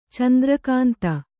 ♪ candra kānta